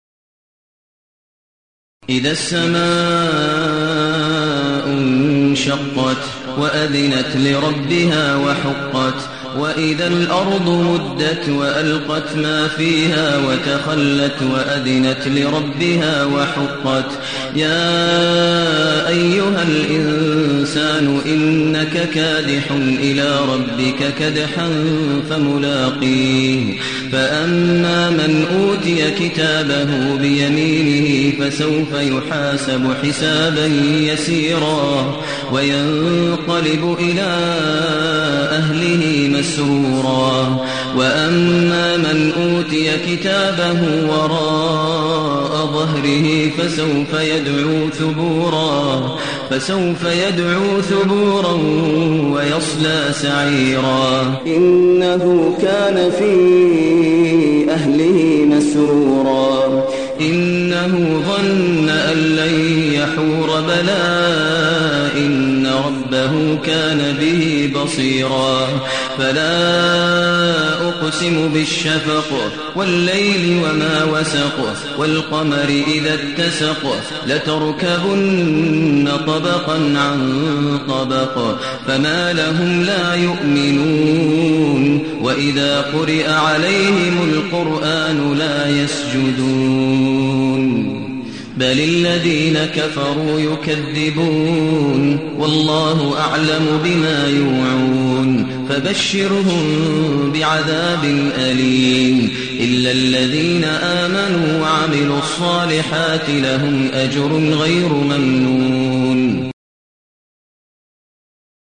المكان: المسجد النبوي الشيخ: فضيلة الشيخ ماهر المعيقلي فضيلة الشيخ ماهر المعيقلي الانشقاق